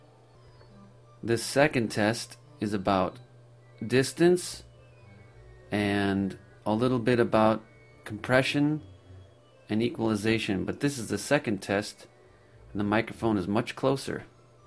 and they're both passable vocal recordings, is that voc 2 sounds much clearer and
voc 2 (closer)
AUD_voice_close_NOcomp.mp3